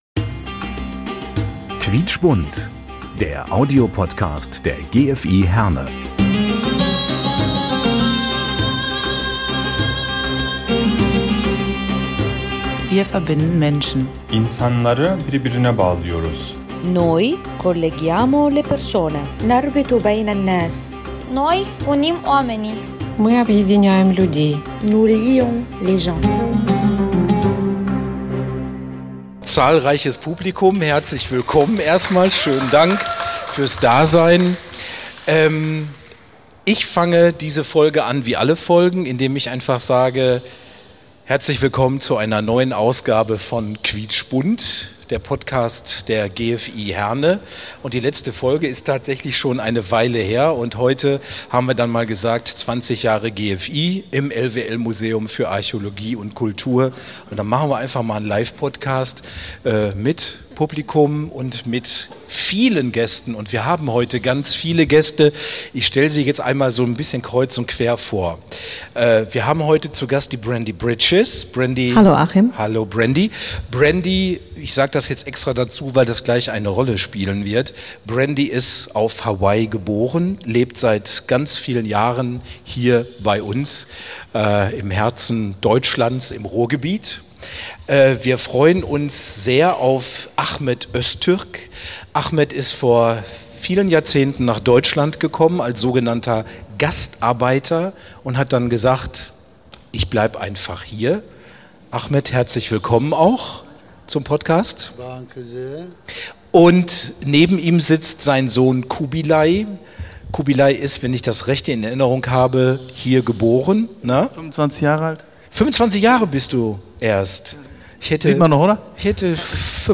Beschreibung vor 1 Jahr 20 Jahre gfi Herne - dazu hatten wir am 29. September 2024 in das LWL Museum für Archäologie und Kultur eingeladen.